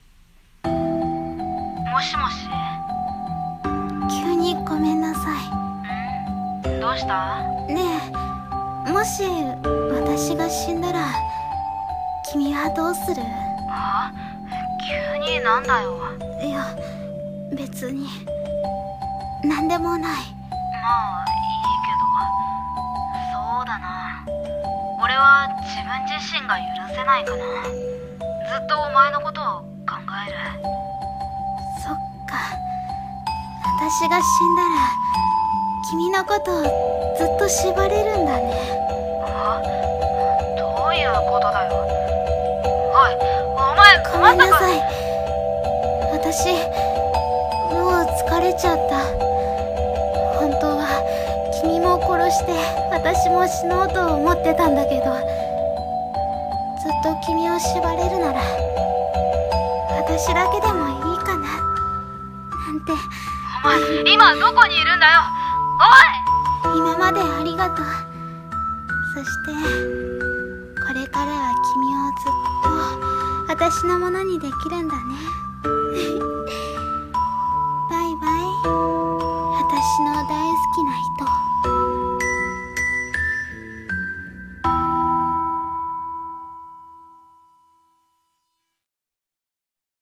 ヤンデレ声劇 ●(ヤンデレ彼女)×○(彼氏)